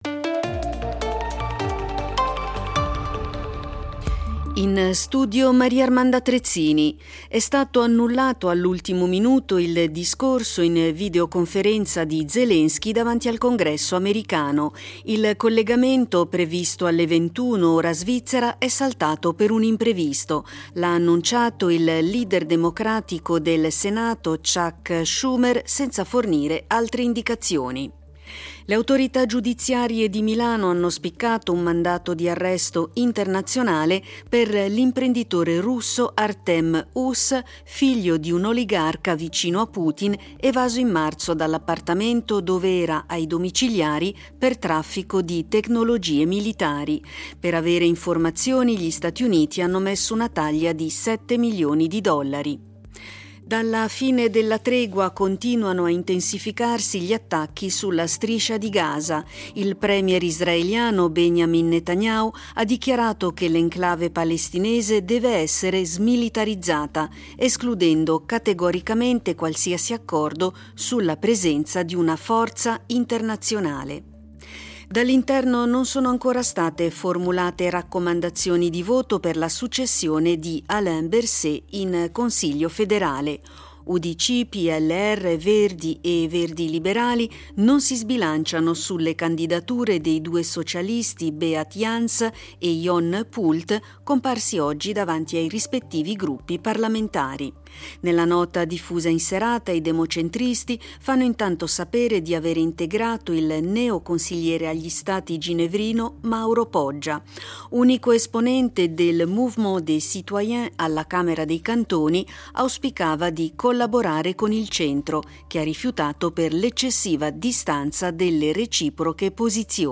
Notiziario delle 22:00 del 05.12.2023